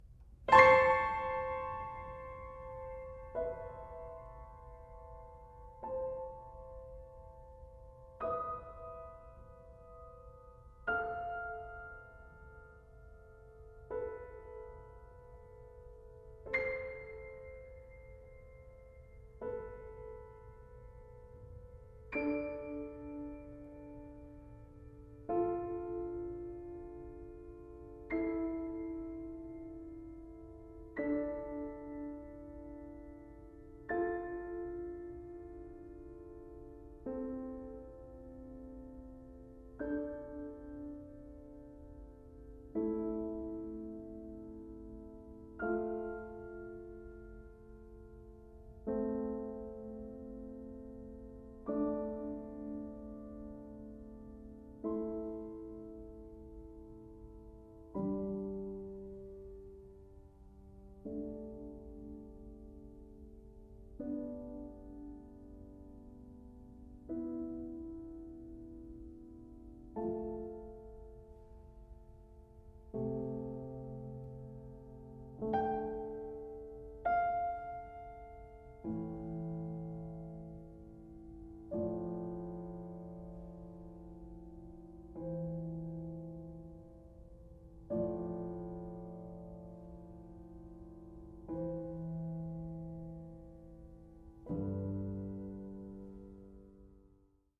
• Genres: Solo Piano